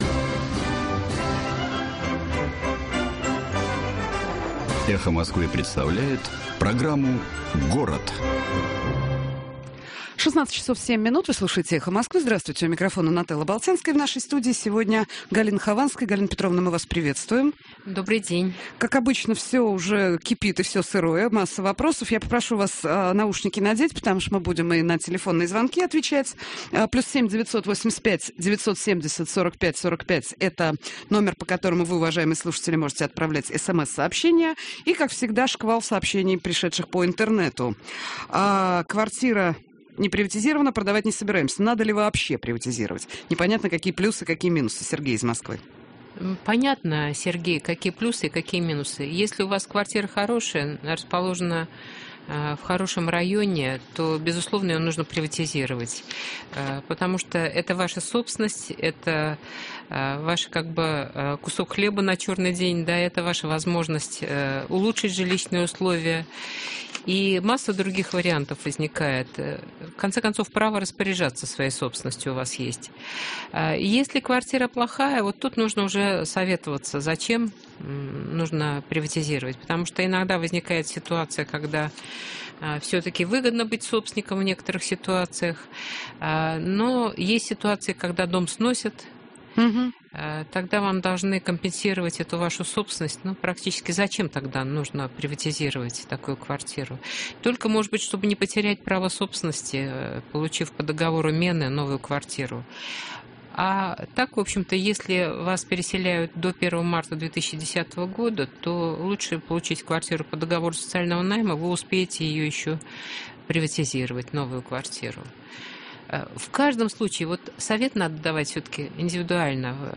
Горячая линия - Галина Хованская - Город - 2008-09-20